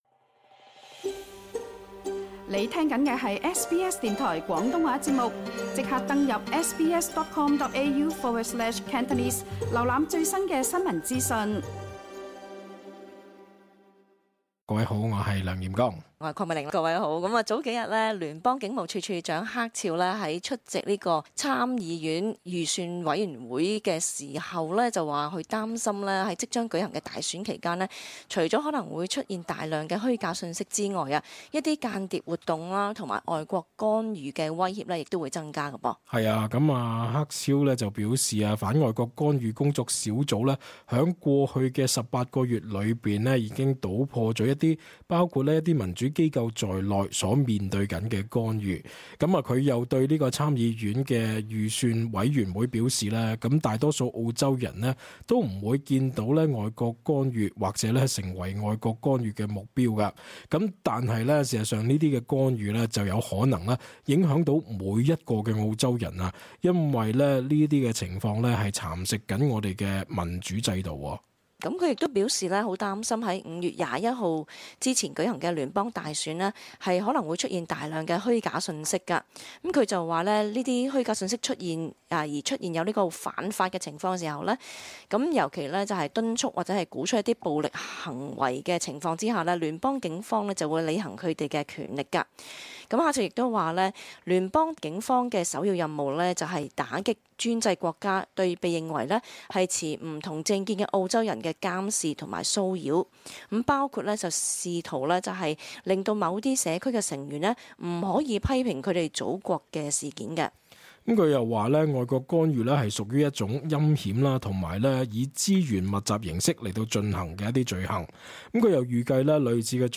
cantonese-_talkback_-_upload_-_feb_17-_final.mp3